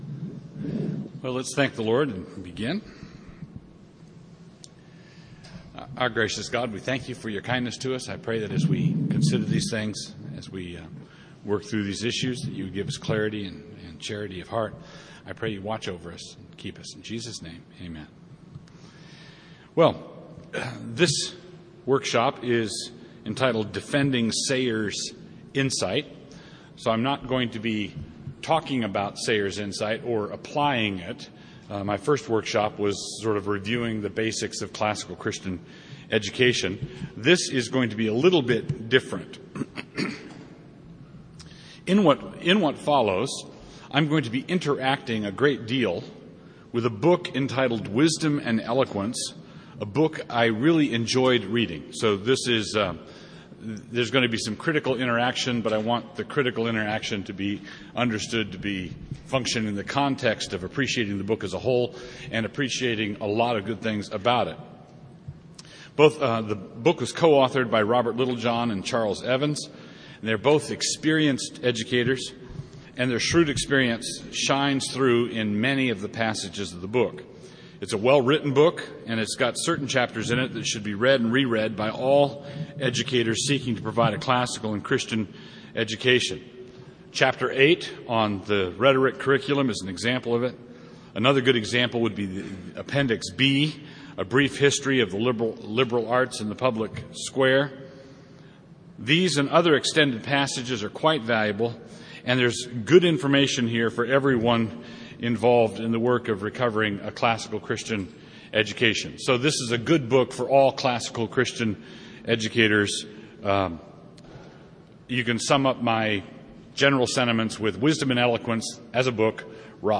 2008 Workshop Talk | 0:55:23 | All Grade Levels, General Classroom
He is the author of numerous books on classical Christian education, the family, and the Reformed faith Speaker Additional Materials The Association of Classical & Christian Schools presents Repairing the Ruins, the ACCS annual conference, copyright ACCS.